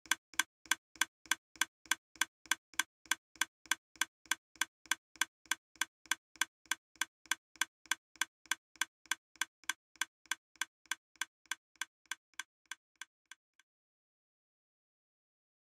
Far away sounds are louder than they should be
In Minecraft, the sounds that are far away from the player seem too intense. And when the sound is played a bit further away, it abruptly gets quiet (See graph 1).
They do not fade out smoothly, in a logarithmic scale as you would expect, but in a linear scale.